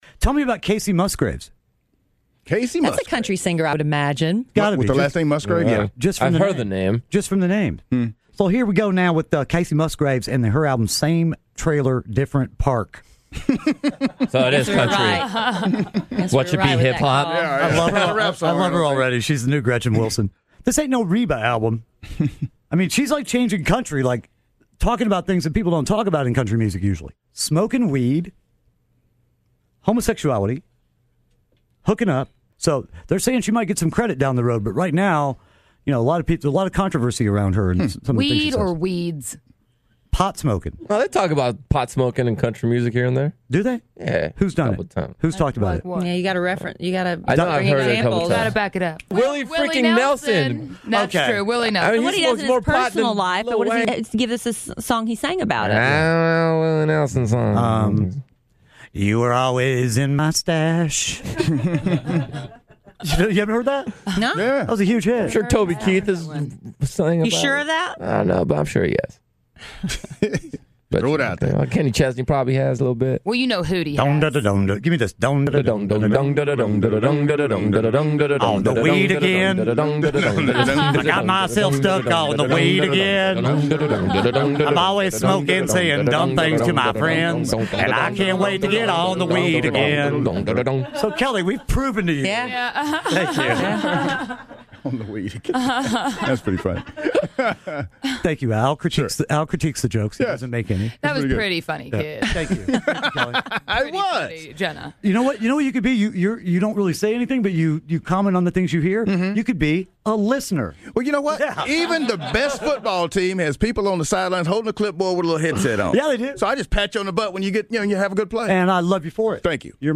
country song